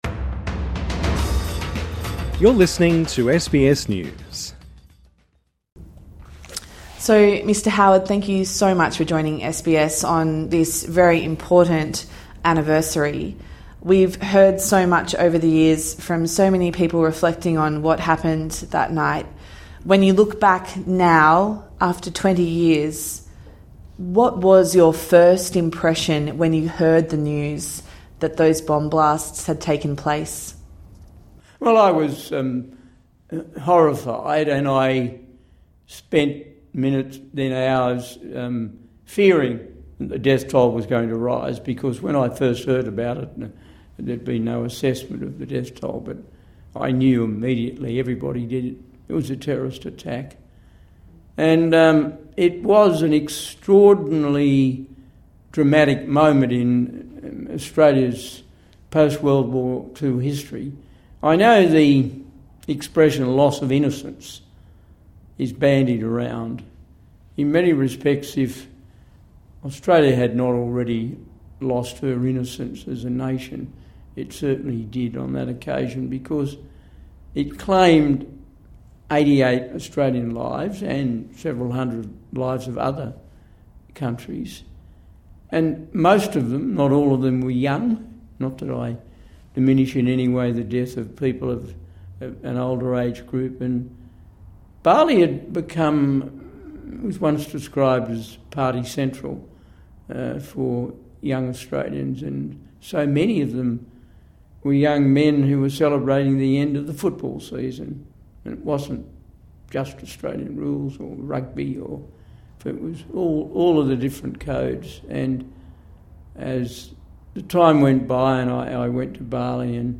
talks to John Howard about the Bali bombings